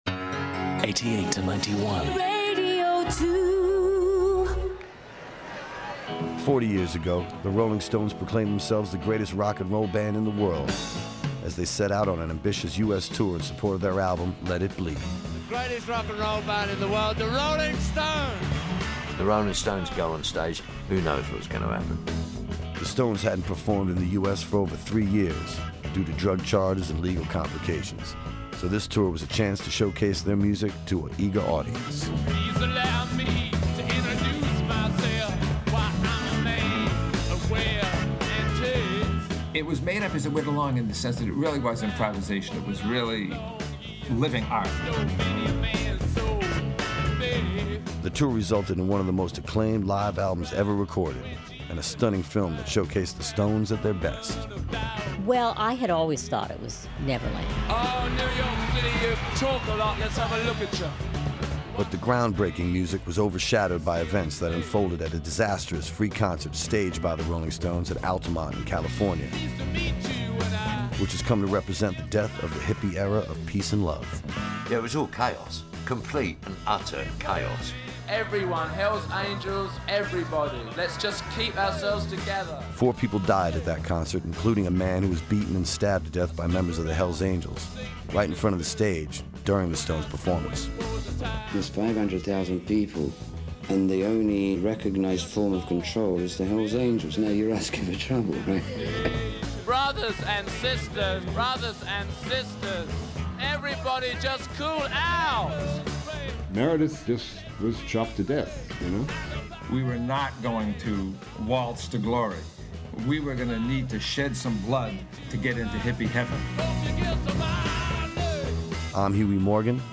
Huey Morgan tells the story of the Rolling Stones’ 1969 Let It Bleed tour, a milestone in rock history that defined an era, and its tragic finale at the Altamont Speedway.